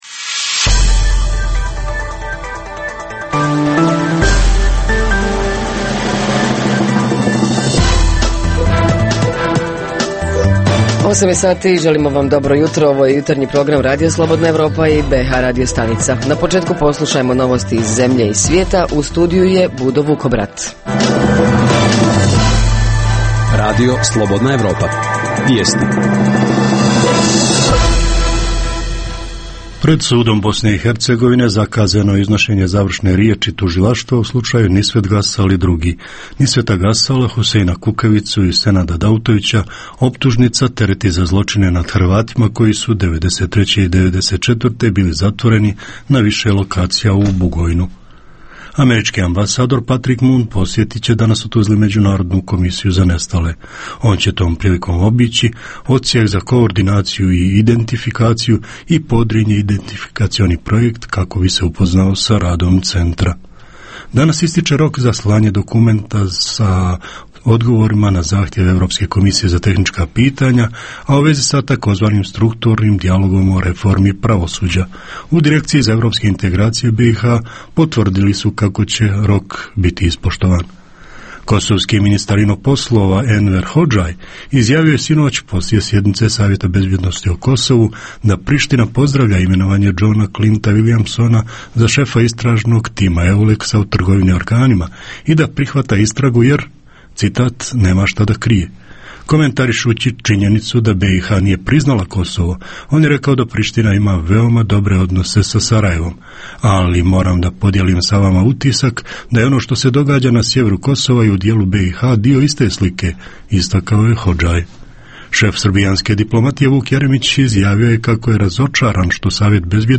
Ovoga jutra pitamo: da li je sve spremno za početak nove školske godine? Reporteri iz cijele BiH javljaju o najaktuelnijim događajima u njihovim sredinama.